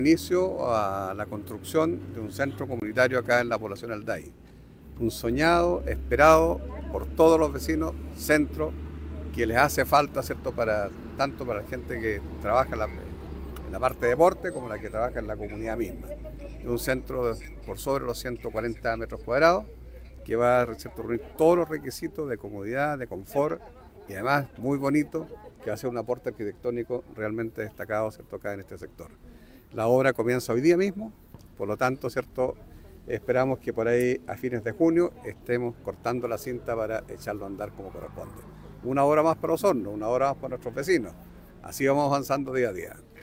El alcalde Jaime Bertin señaló que este acto marca el inicio oficial de la construcción del centro comunitario, financiado gracias al Fondo Regional de Iniciativa Local por un monto cercano a los 142 millones de pesos.